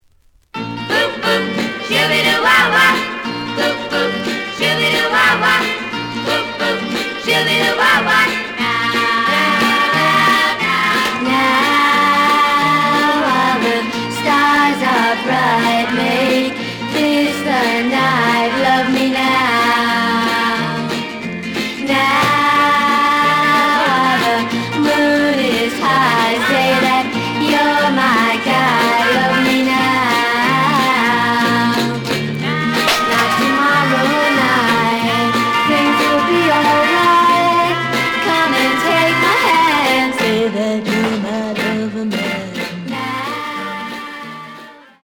試聴は実際のレコードから録音しています。
●Genre: Rhythm And Blues / Rock 'n' Roll
●Record Grading: VG (両面のラベルに若干のダメージ。傷は多いが、プレイはまずまず。)